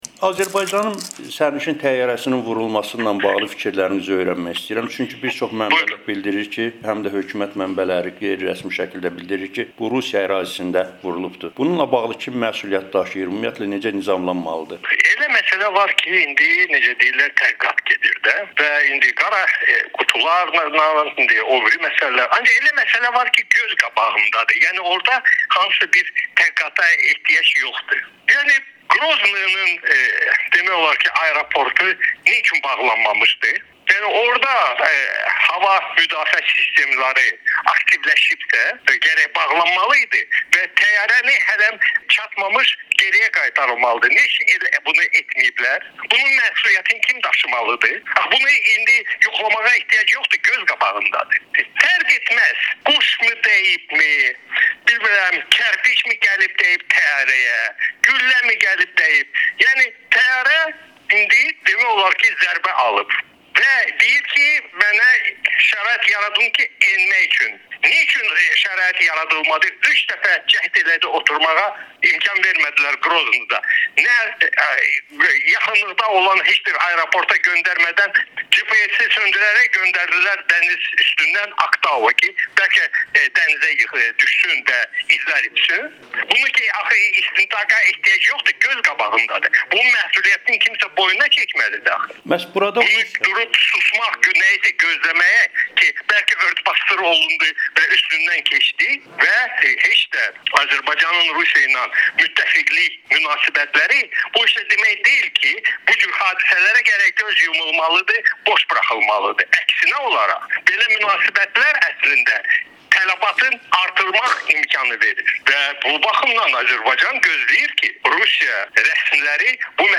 Milli Məclisin deputatı Rasim Musabəyov Amerikanın Səsinə bildirib ki, Azərbaycan təyyarəsi Rusiya ərazisində, Qroznı səmasında vurulub.